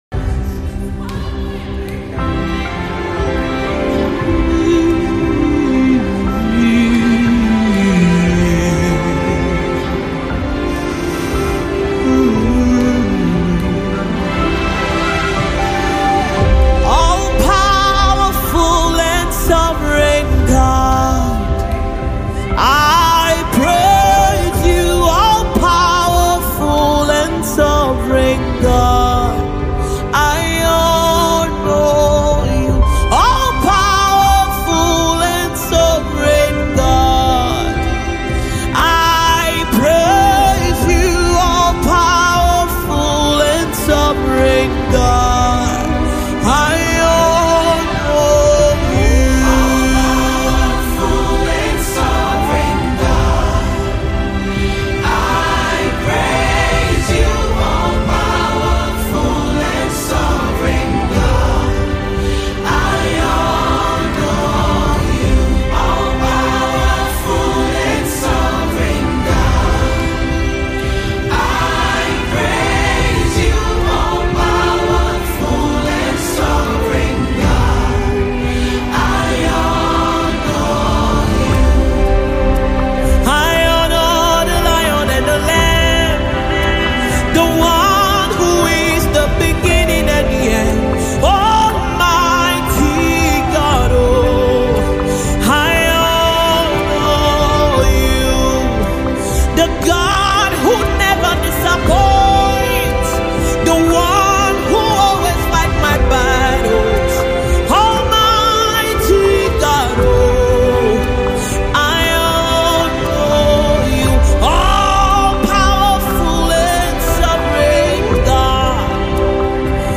a soul-stirring gospel project with 10 powerful tracks
With her strong voice and heartfelt lyrics